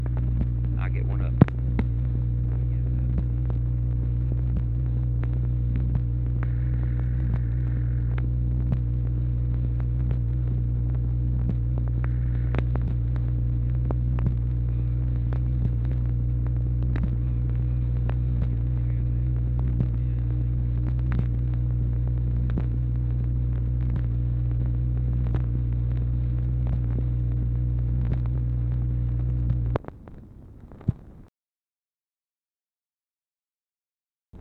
ONLY AUDIBLE WORDS ARE "I'LL GET ONE OF THEM"